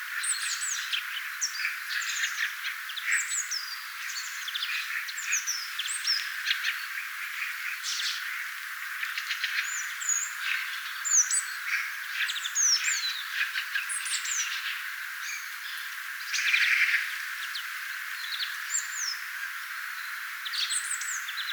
kuusitiaisen aika hyvä
taigauunilintumatkin
aika_hyva_taigauunilintumatkinta_kuusitiaiselta.mp3